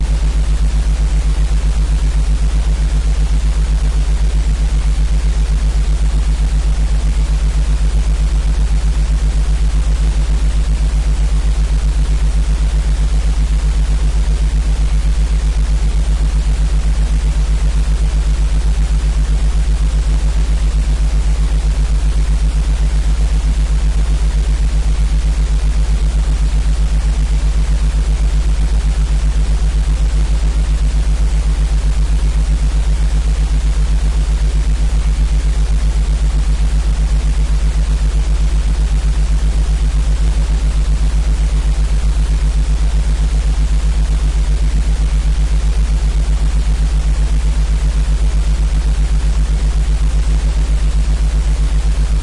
theta脑波深度放松
描述：由两个正弦波形成的theta脑波声音。 音调平缓地移动，以产生缓慢变化。 你可以叠加其他声音用来促进深度放松。
标签： 脑波 放松 深度 双耳
声道立体声